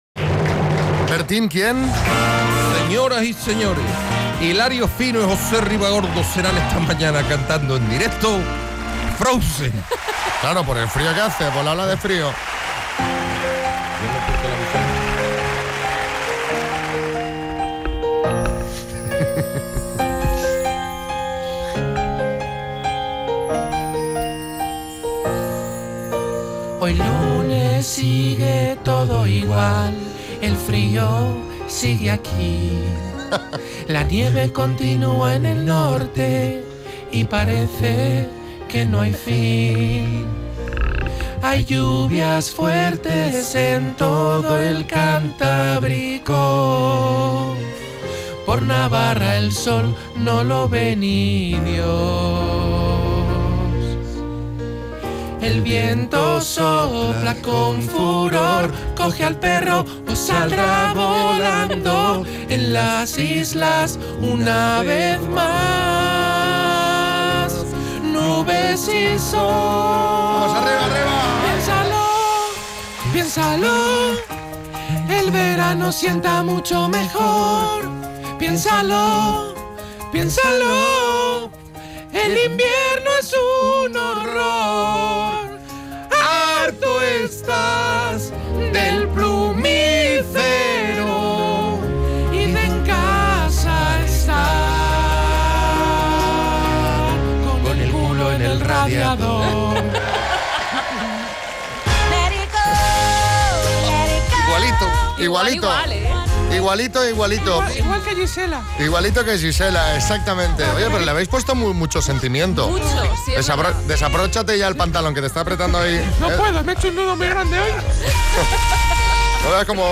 una particular versión del popular tema